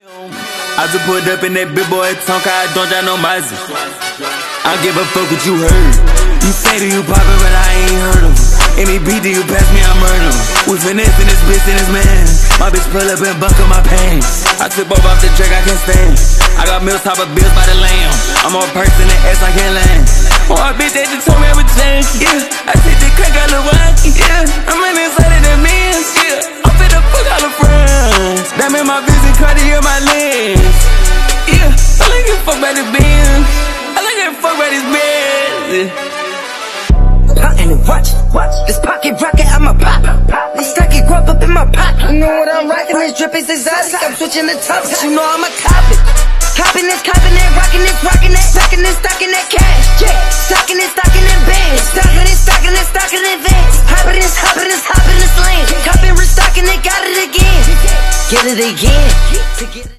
A beautiful city scape with sound effects free download
A beautiful city scape with an 8d audio